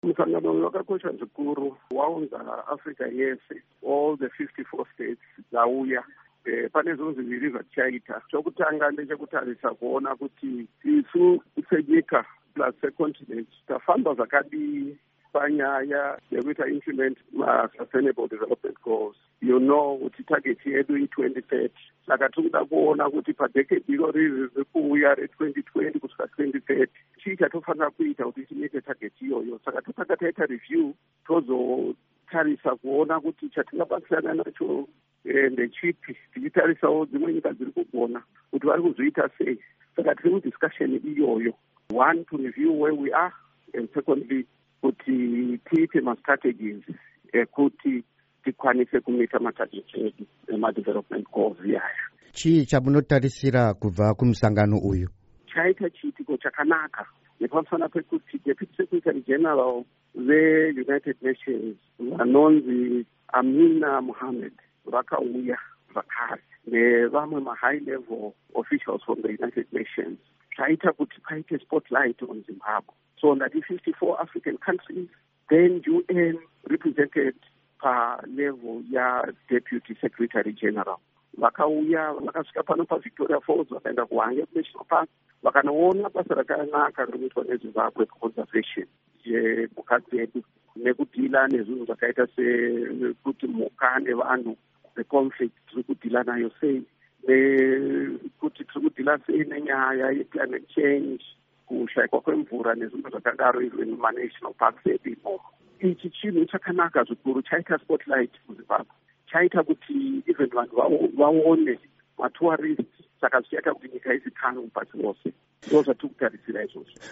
Hurukuro naMuzvinafundo Paul Mavima